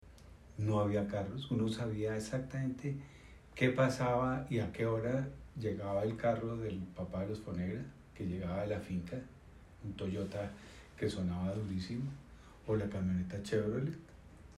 Bienvenidos al archivo digital 'Memoria Sonora de Bogota: Teusaquillo y Chapinero en las décadas de 1960 y 1970'. Esta colección de historias orales, recoge las memorias sonoras de habitantes de las dos localidades en archivos mp3 con el fin de utilizarlas para investigaciones sobre la historia y cultura de Bogotá.
MG_sonidovecinofonegra.mp3